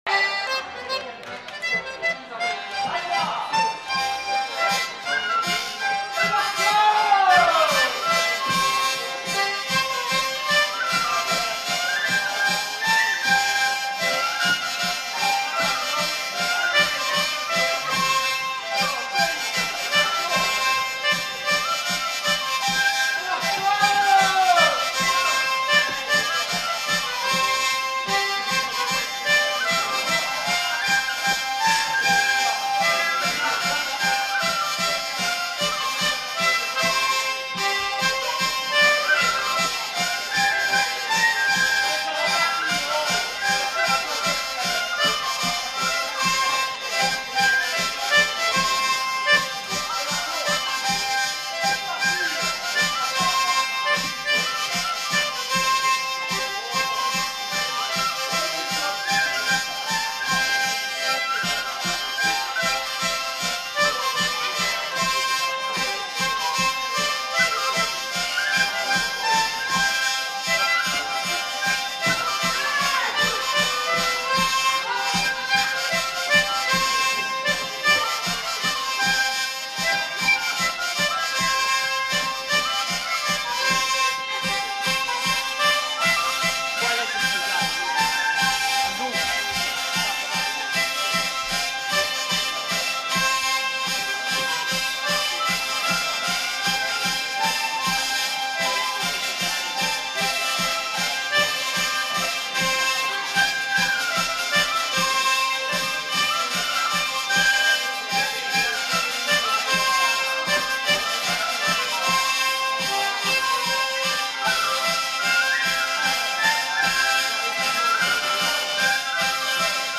Aire culturelle : Gabardan
Lieu : Houeillès
Genre : morceau instrumental
Instrument de musique : vielle à roue ; accordéon diatonique ; flûte à bec ; violon
Danse : mazurka